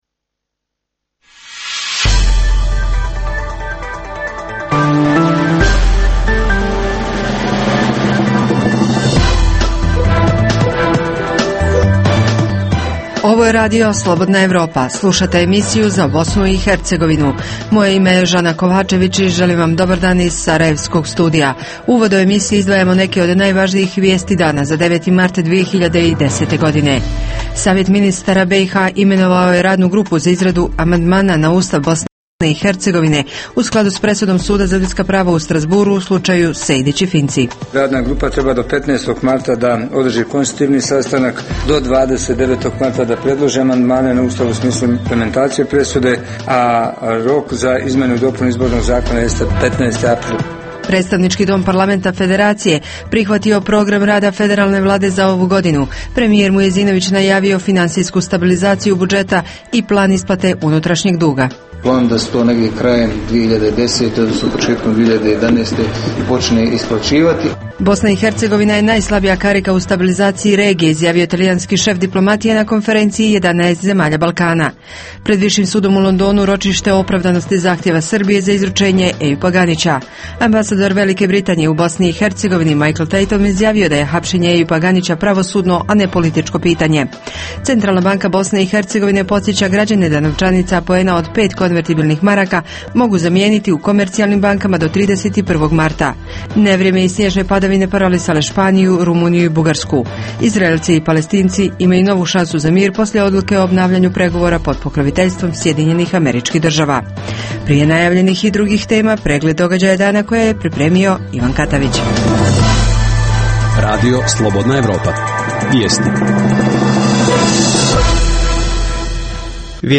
U današnjoj emisiji poslušajte intervju sa predsjednikom novoformirane političke stranke Savez za bolju budućnost, Fahrudinom Radončićem.